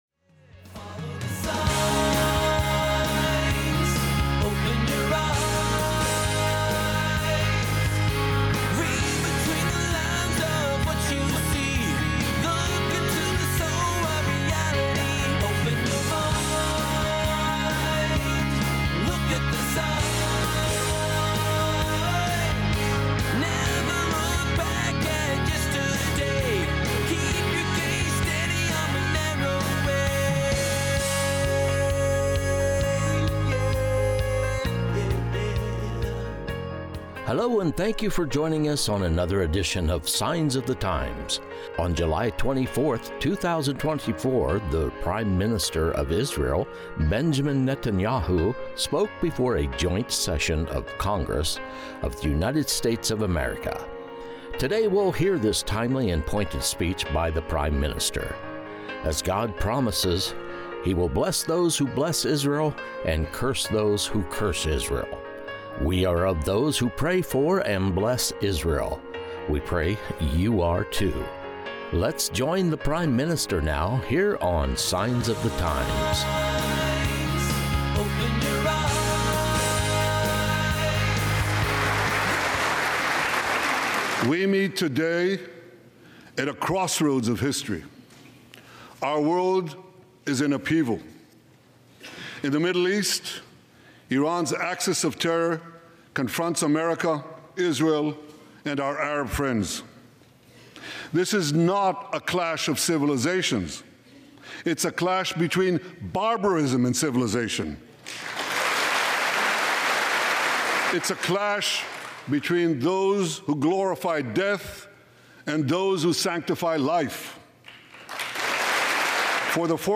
Ep. 321 - Guest Recording | Prime Minister Benjamin Netanyahu | Addresses Joint Session of Congress